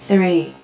Countdown_03.wav